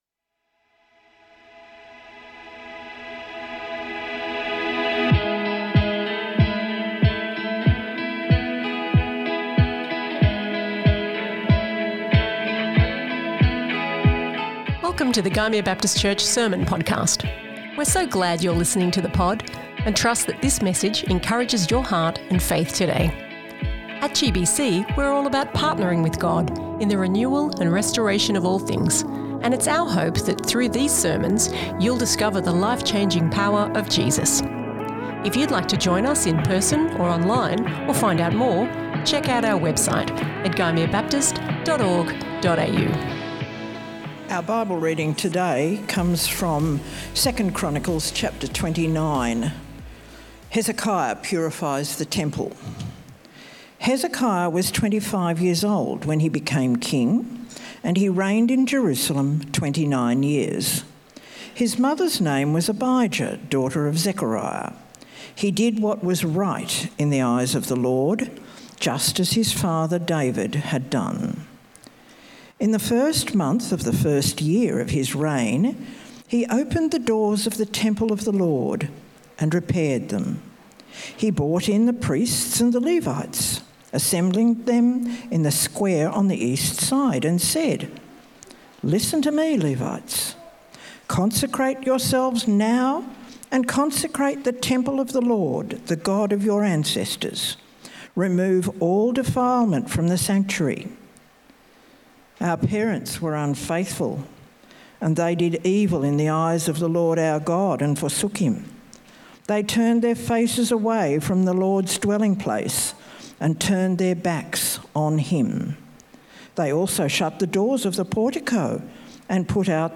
GBC | Sermons | Gymea Baptist Church